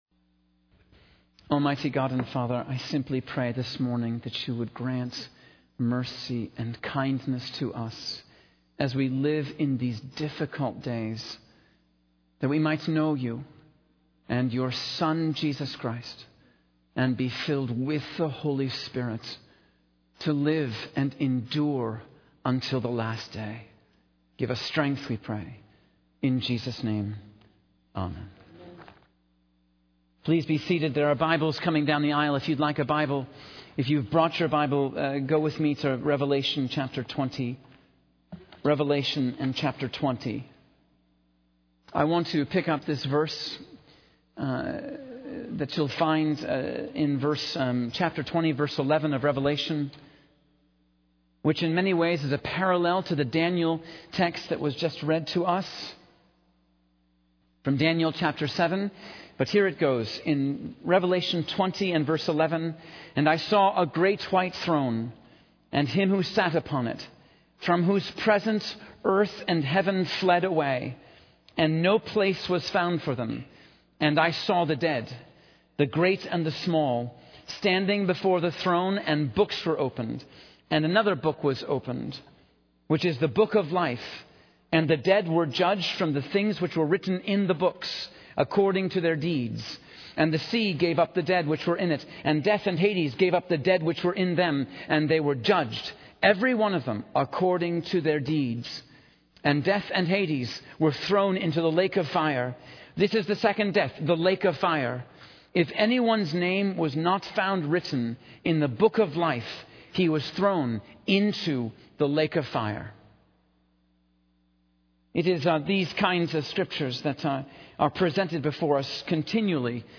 In this sermon, the speaker discusses the importance of motivational speaking in today's society.